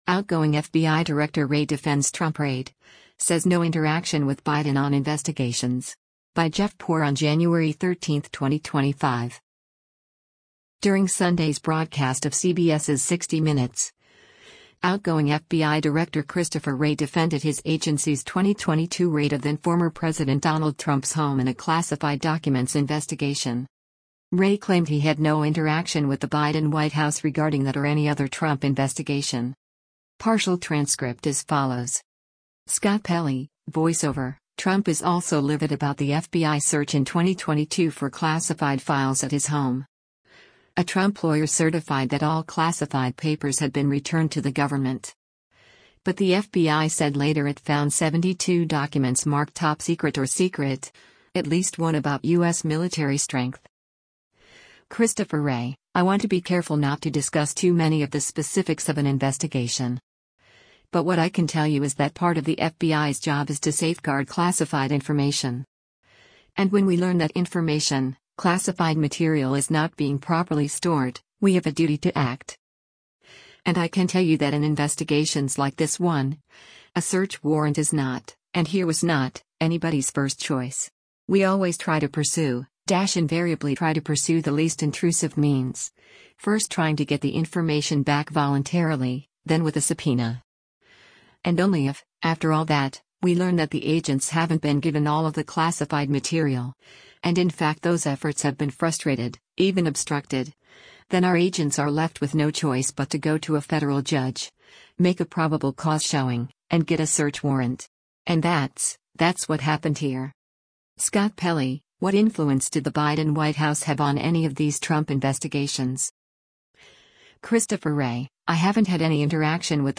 During Sunday’s broadcast of CBS’s “60 Minutes,” outgoing FBI Director Christopher Wray defended his agency’s 2022 raid of then-former President Donald Trump’s home in a classified documents investigation.